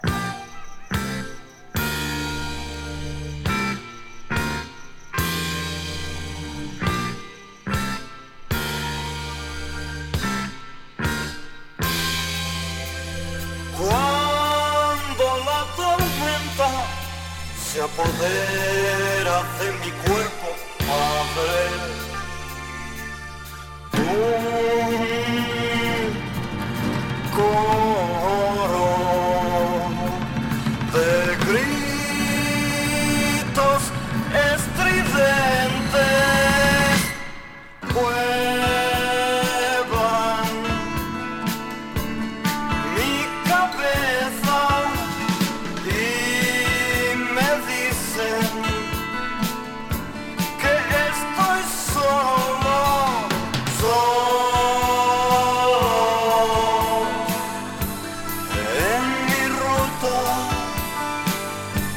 超絶テクニックが光りつつ、程よいサイケ質感がGood。